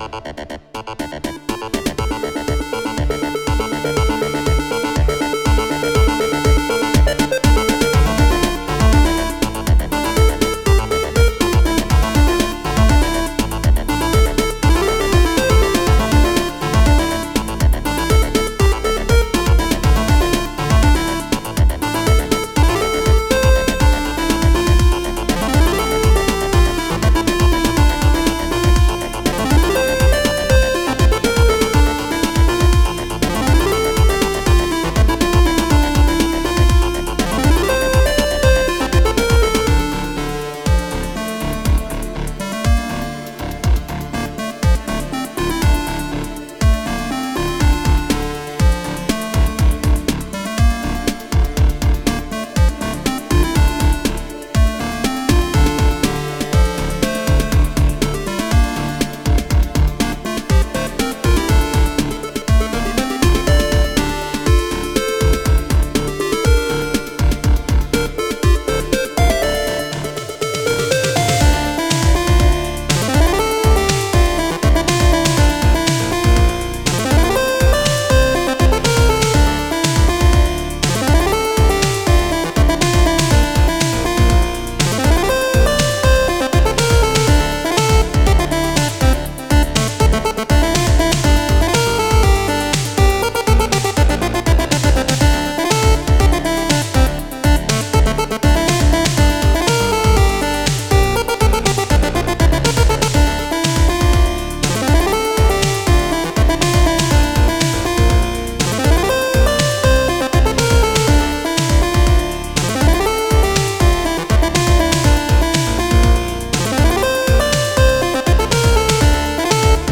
hybrid chiptune loop